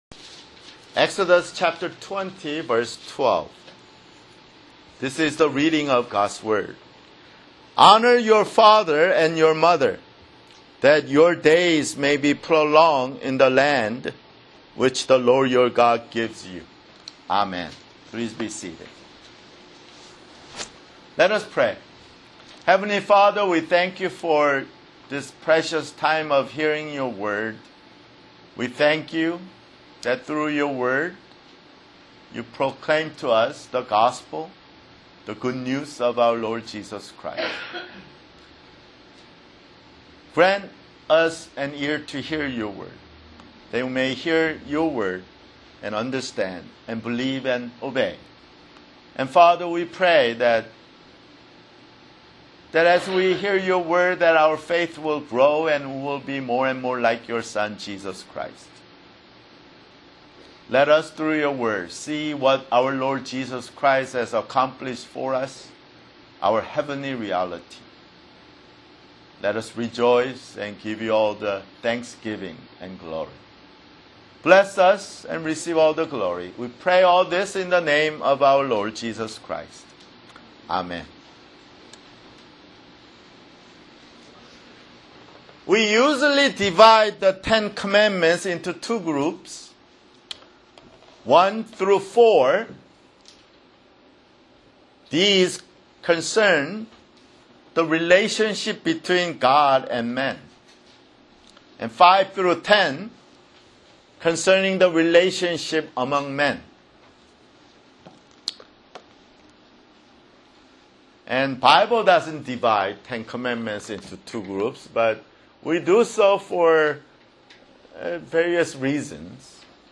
[Sermon] Exodus (52)